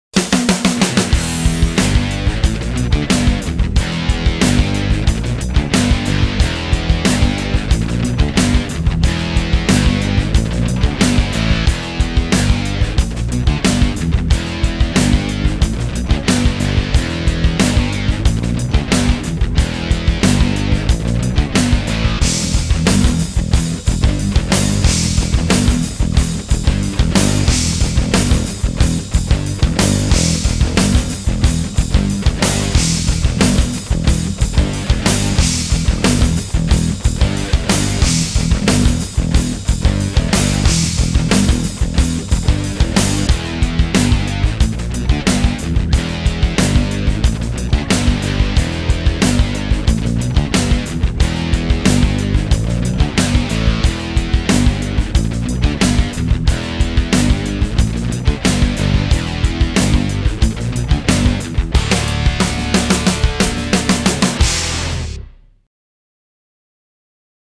Backing-Track.mp3